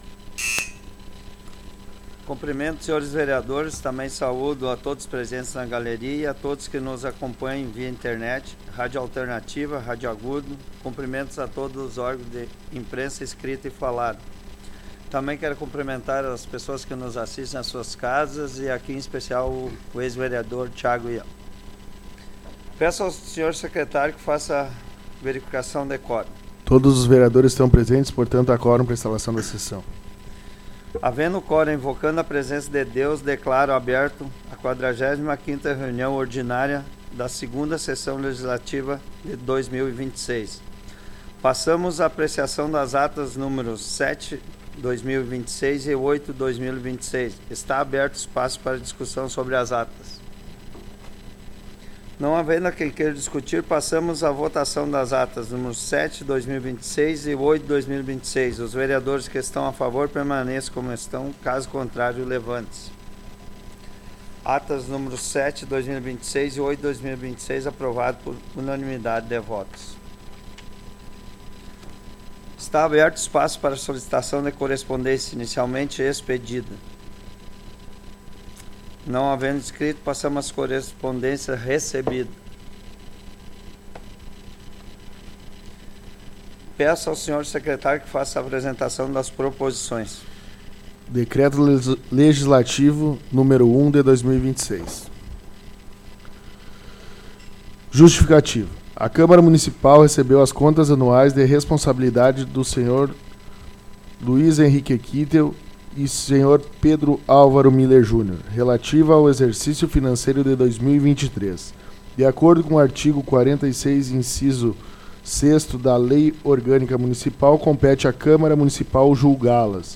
Áudio da 45ª Sessão Plenária Ordinária da 17ª Legislatura, de 02 de março de 2026